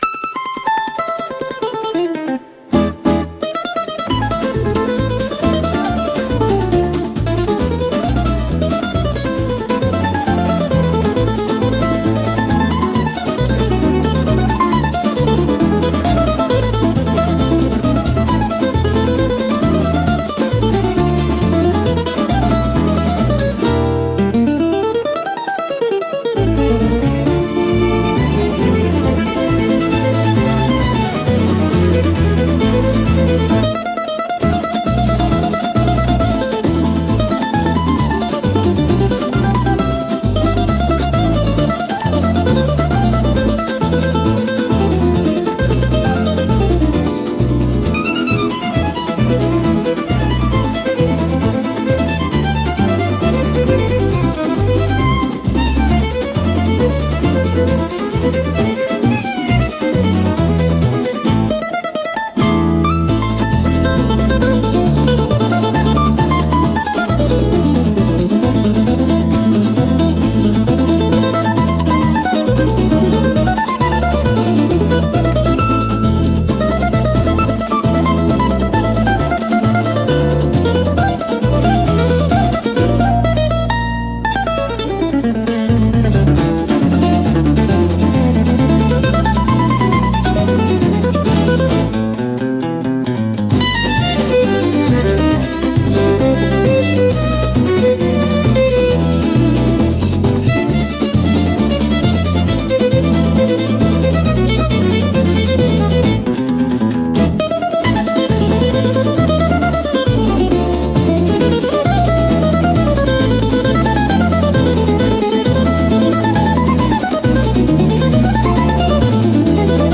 疾走感のあるショーロ楽曲です。
検索用キーワード BGM ショーロ マンドリン 弦楽器 アコースティックギター パンデイロ かっこいい 情熱的 元気